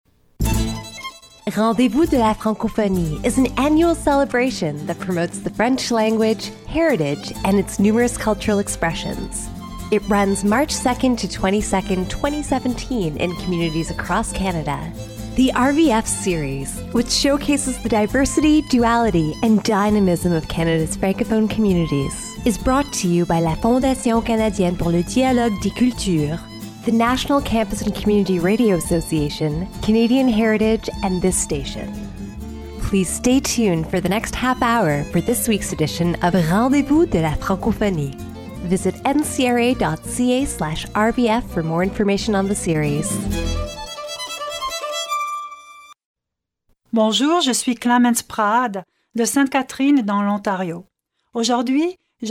Description: This show is interviews with five francophone women who are actively engaged in cultural venues in the area of Niagara.
Type: Weekly Program